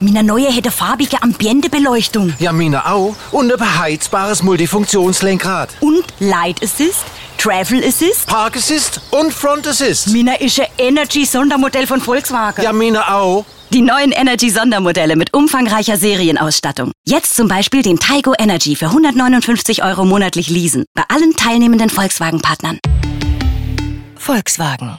Sprecherin, Synchronsprecherin